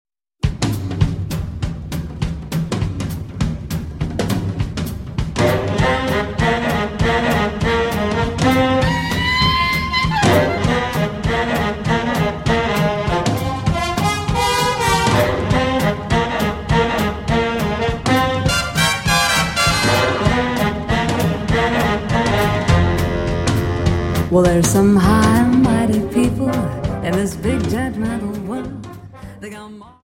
Quickstep 50 Song